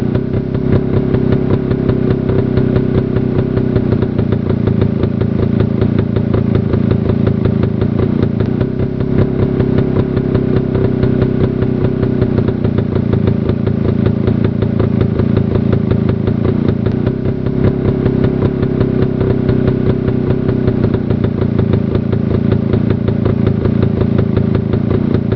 Jawa CZ 250 cc ja